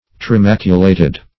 Search Result for " trimaculated" : The Collaborative International Dictionary of English v.0.48: Trimaculated \Tri*mac"u*la`ted\, a. [Pref. tri- + maculated.] Marked with three spots, or maculae.